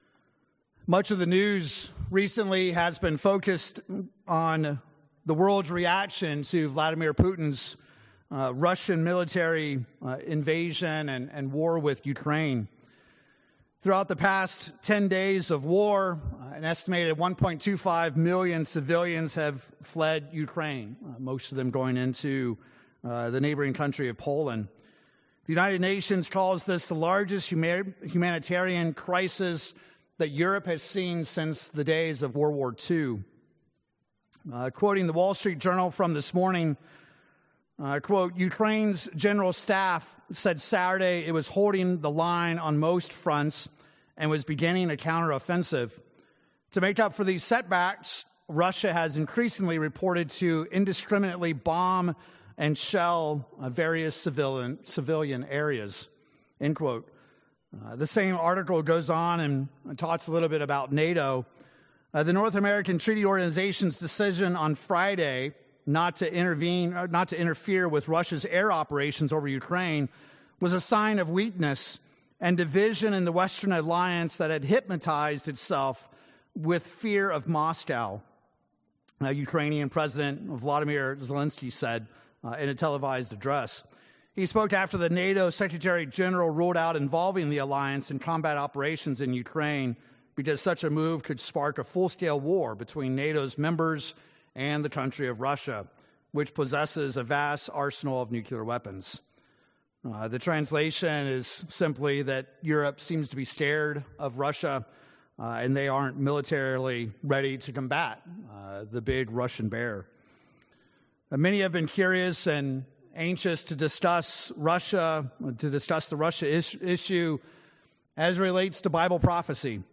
In this sermon we will examine the role that Russia plays in end-time prophecy.